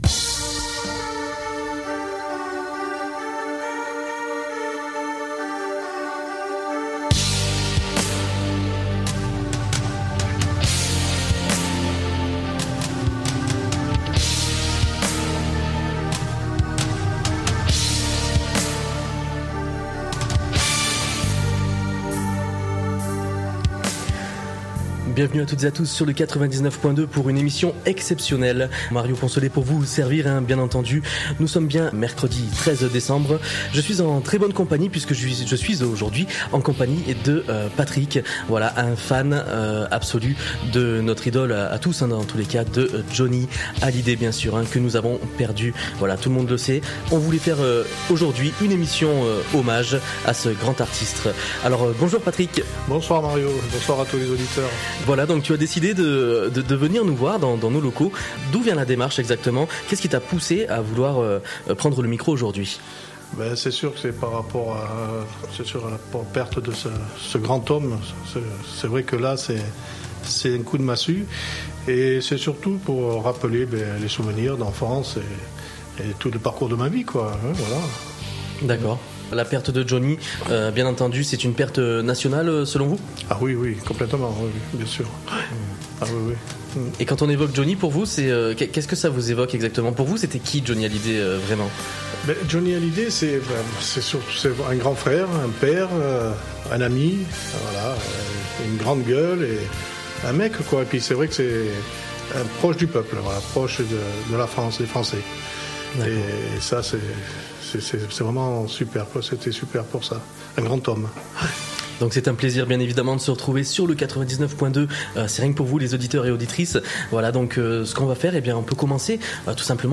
Retrouvez aussi dans cette émission la première télé de Johnny, une interview de son guitariste Yarol Poupaud, des témoignages de personnalités et plus encore...